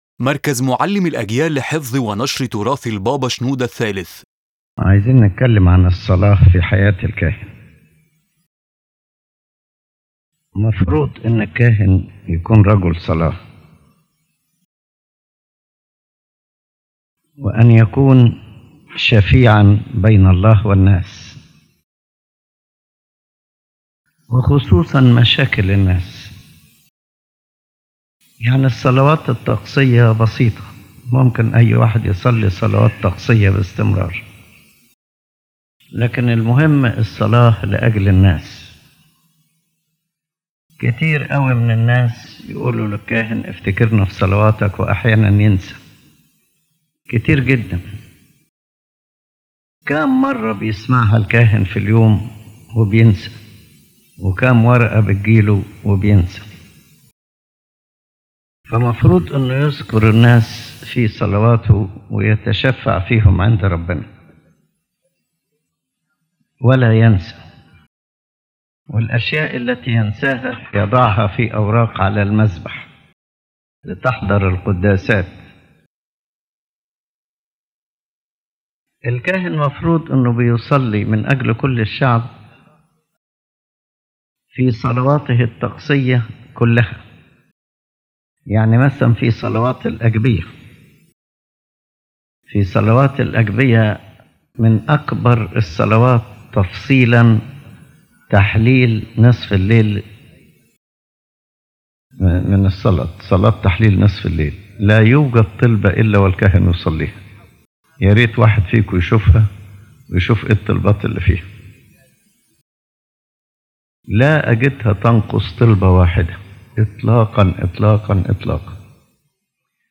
The lecture affirms that the priest should be a man of prayer and an intercessor between God and people, and that liturgical prayers alone are not enough if they are not accompanied by personal prayer that comes from the heart dedicated to the people and the ministry.